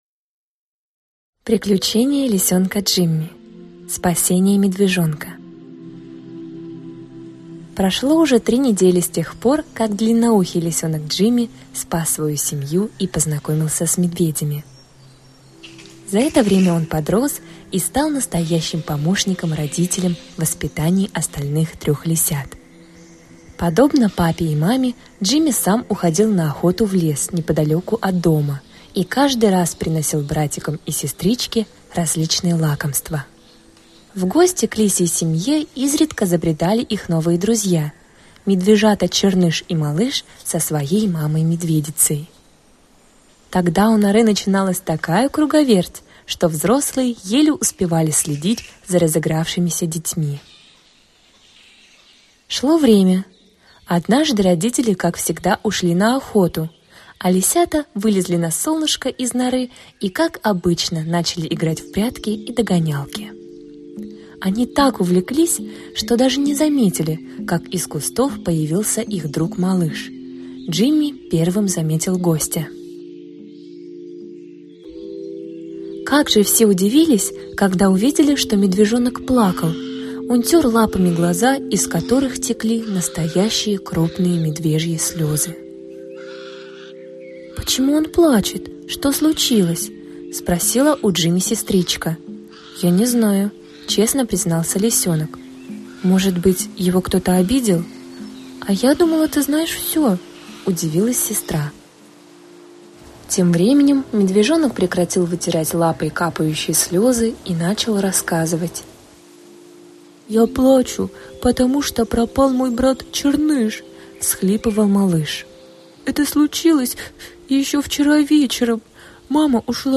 Аудиокнига Приключения лисёнка Джимми. Спасение медвежонка | Библиотека аудиокниг